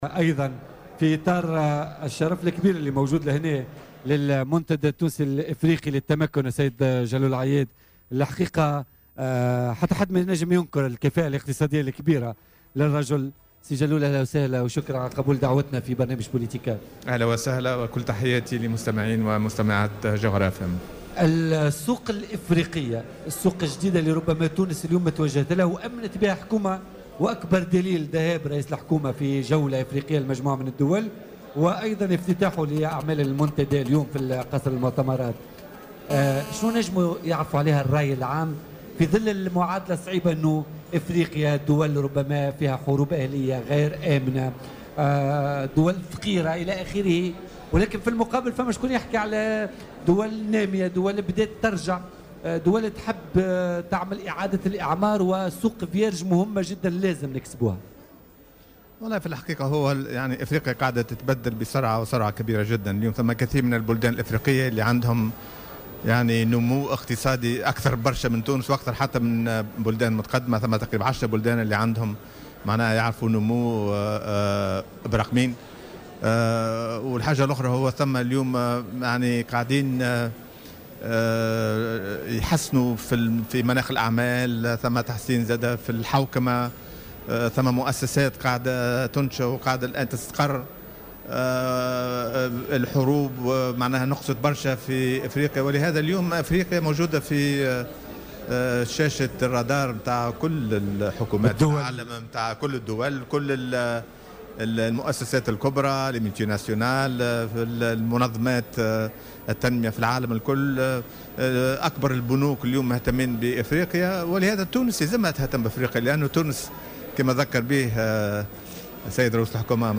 وذكر ضيف "بوليتيكا" على هامش المنتدى التونسي الافريقي للتمكن، بمكانة الزعيم الحبيب بورقيبة في هذه الدول مشددا على اهمية استرجاع تونس لمكانتها في افريقيا.